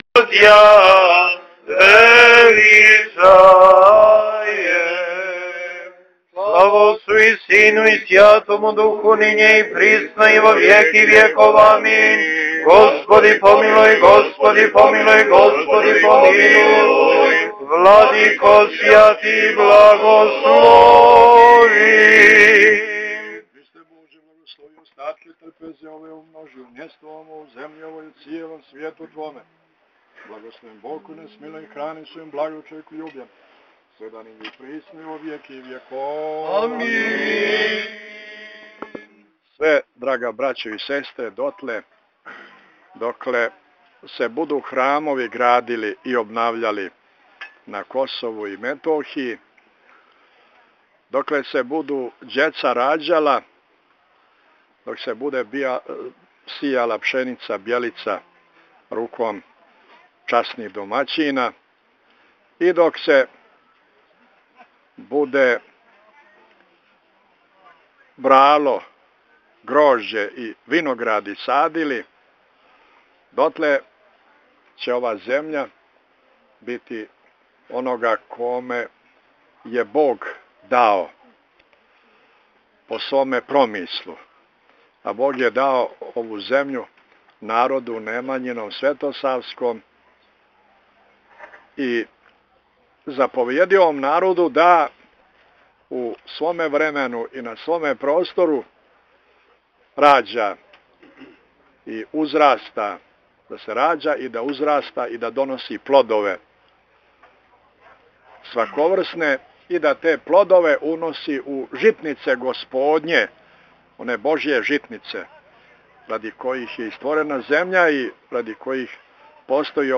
Веседа Митрополита Амфилохија за трпезом 8МБ